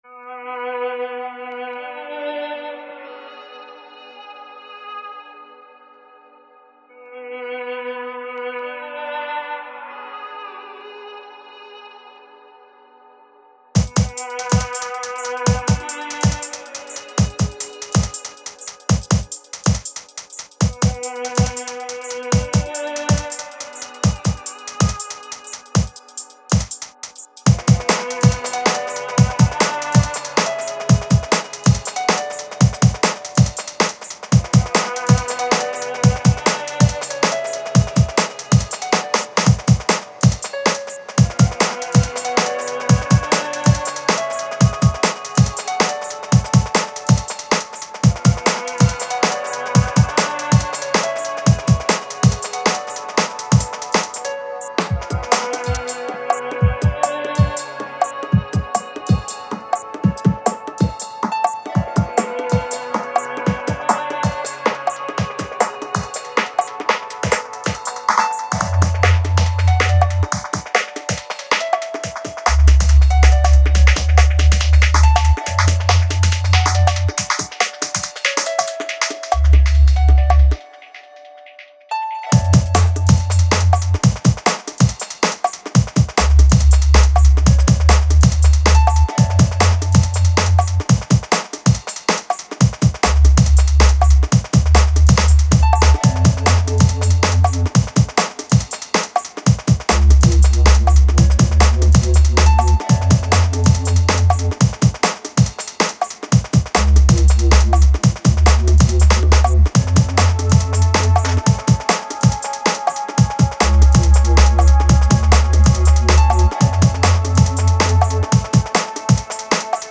good congas too. Nice low bass.
Good atmos and build up nicely.